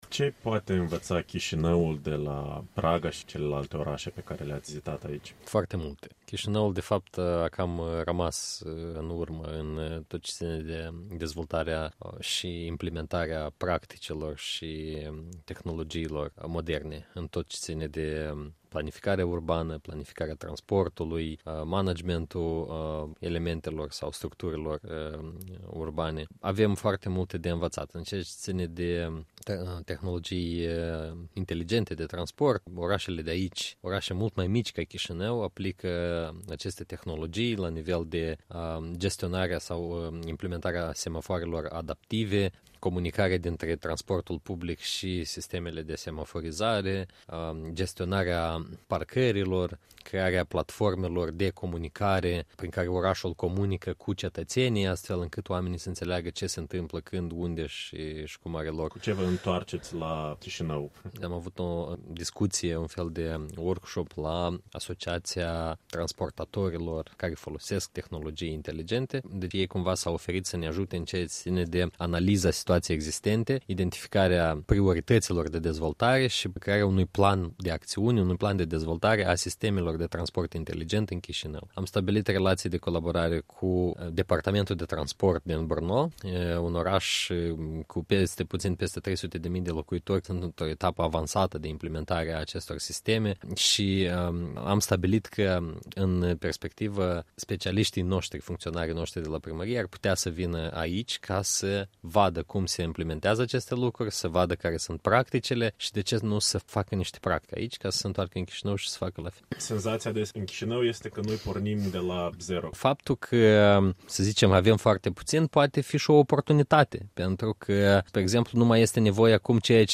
Interviu cu Victor Chironda